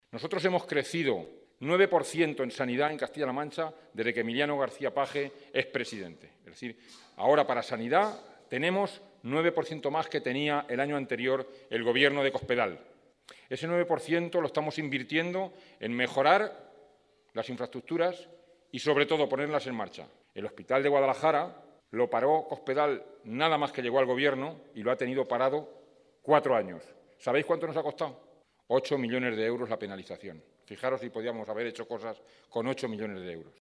El candidato del PSOE de Guadalajara al Congreso, Pablo Bellido, ha asegurado esta tarde en Mondéjar que “al que se emociona con las alcachofas, hay que mandarle a esparragar”, en referencia a las declaraciones que ha hecho esta semana el presidente del Gobierno en funciones, Mariano Rajoy.
Cortes de audio de la rueda de prensa